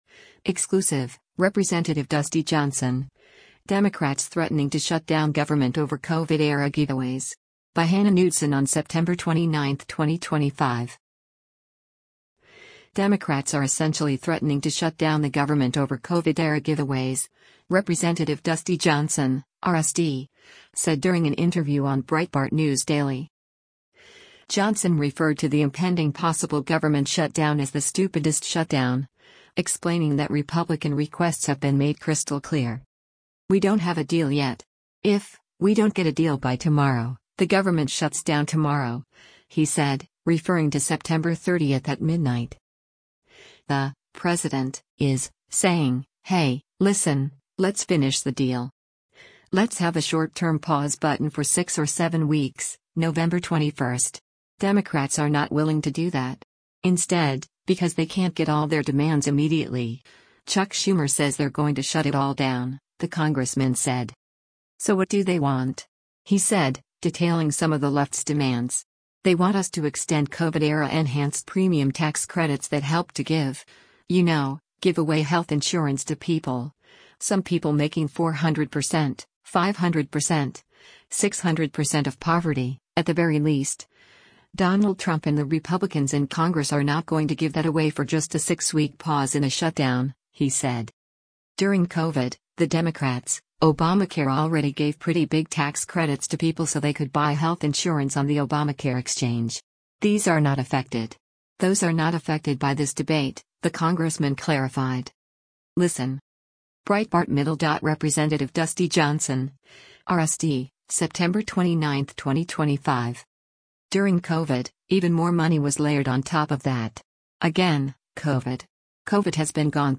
Democrats are essentially threatening to shut down the government over “COVID-era giveaways,” Rep. Dusty Johnson (R-SD) said during an interview on Breitbart News Daily.